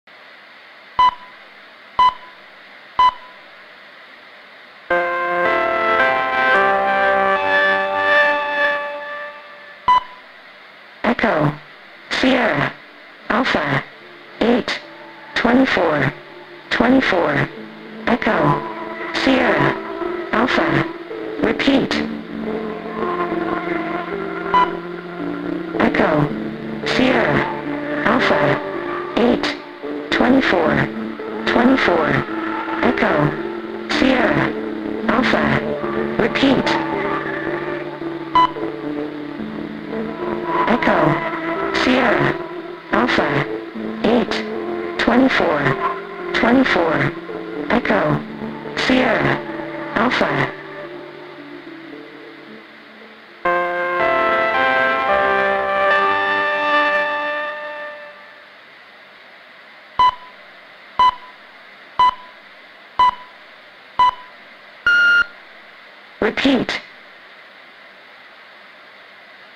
Numbers Station operational. System diagnostics sound effects free download